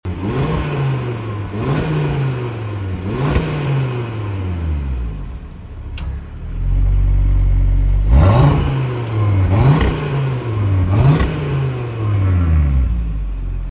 LISTEN TO THE 5-CYLINDER SYMPHONY!
• RS Sports Exhaust System with Black Oval Tips
• 2.5TFSI 5-Cylinder Turbo Engine (400PS & 500NM)
audi-rs3-8y-vorsprung-exclusive-sportback-nogaro-blue-avl-sound-clip.mp3